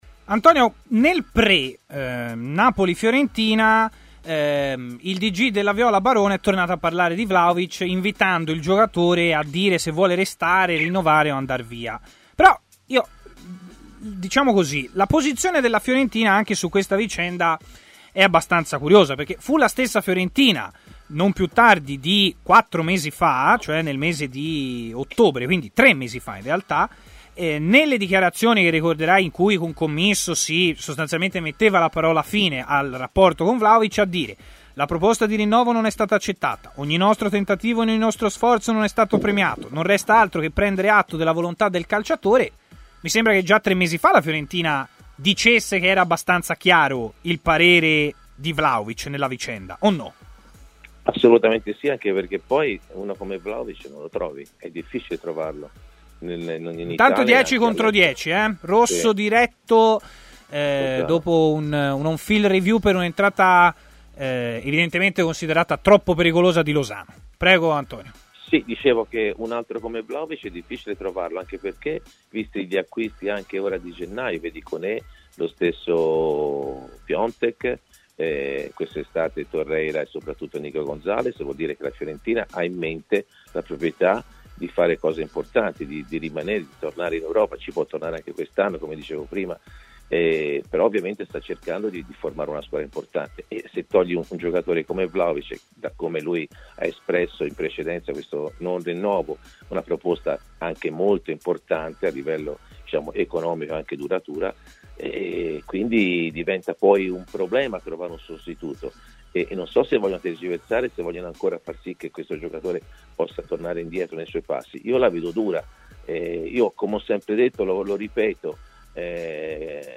L'ex giocatore e allenatore Antonio Di Gennaro, opinionista di TMW Radio, è intervenuto durante Stadio Aperto, iniziando dall'errore di Dragowski che ha causato la sua espulsione: "Non so se il pallone l'ha chiamato, sul gol invece è un suo errore tecnico anche se ci ha pensato Mertens con un gol dei suoi".